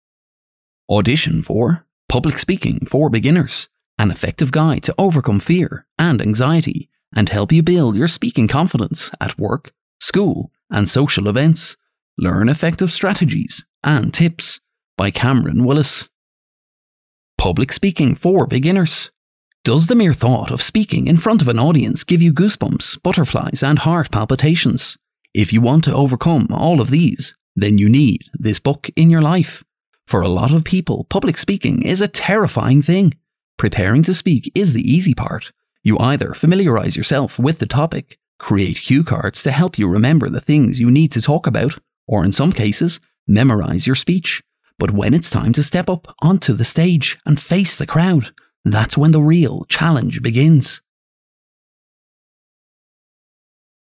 irisch
Sprechprobe: Sonstiges (Muttersprache):
I've a comedic style naturally but I can also do slower, more serious pieces when required.